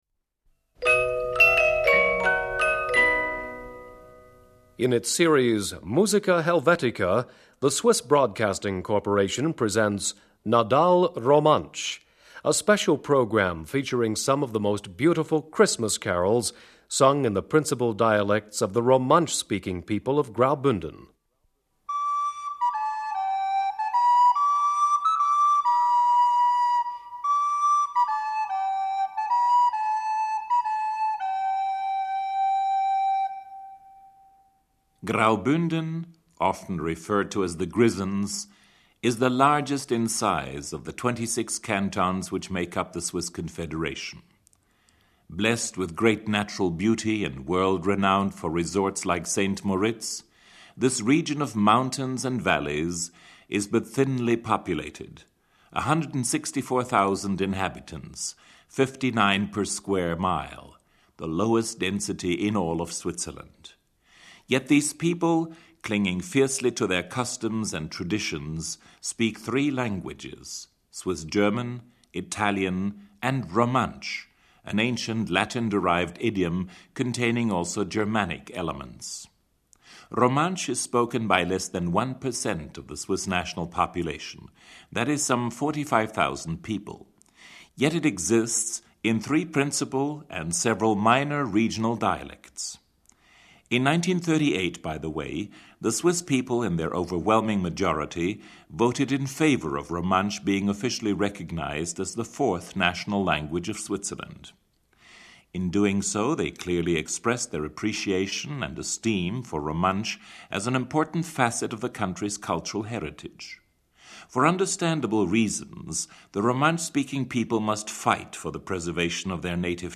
Baritone. 5.
Bass. 9.